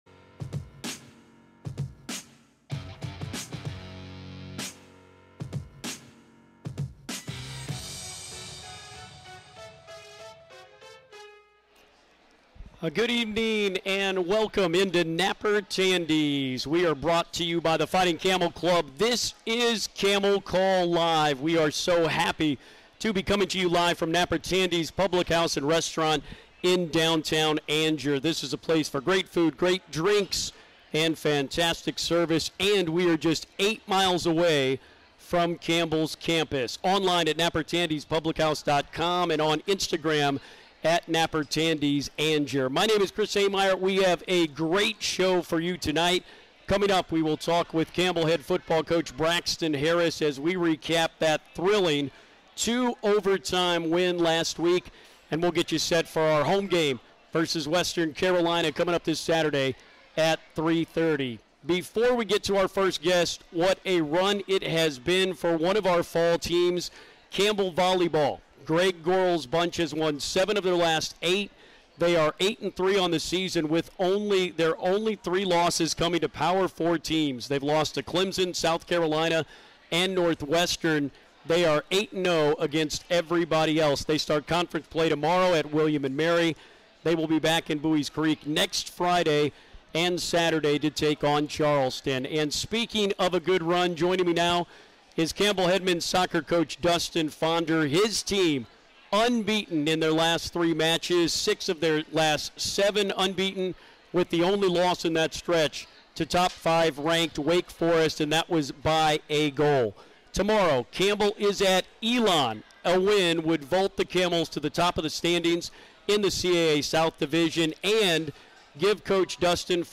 recorded live at Napper Tandy's in downtown Angier.